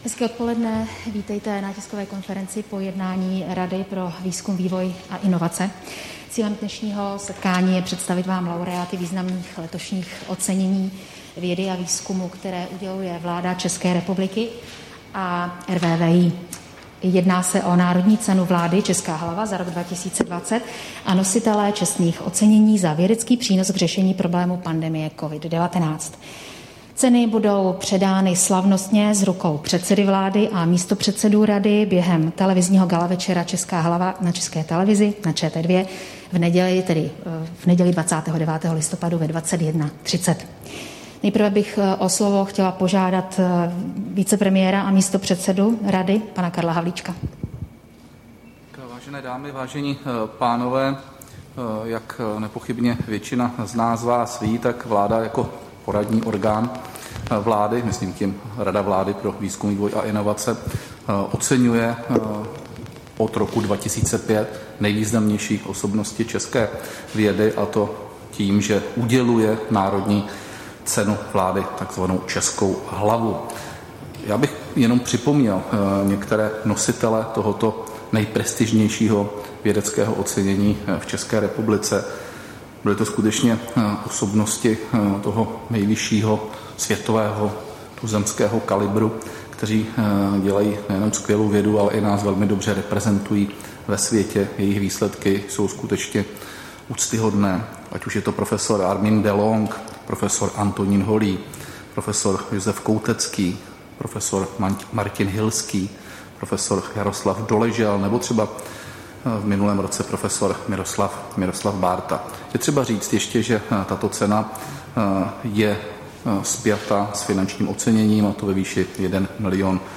Tisková konference po jednání RVVI, 27. listopadu 2020